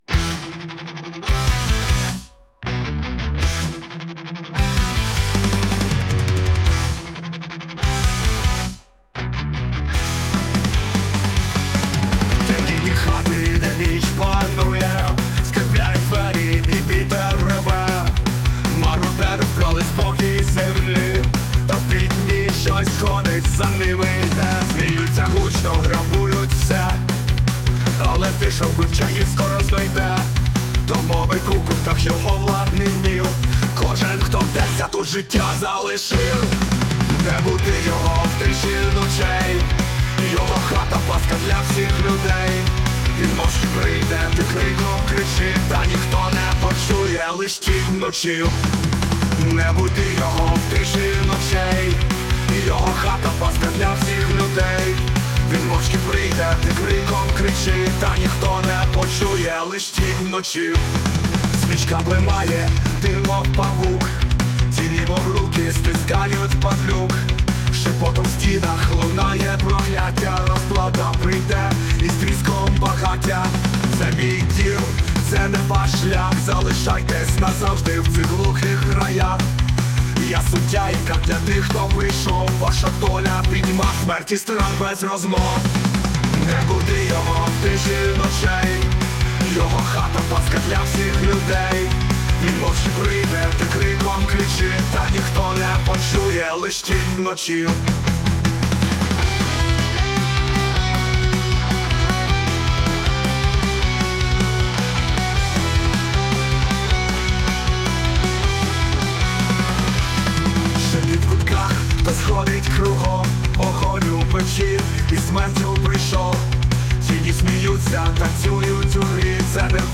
ТИП: Пісня
СТИЛЬОВІ ЖАНРИ: Епічний
hi hard rock цікавий вибір, навіть дещо несподіваний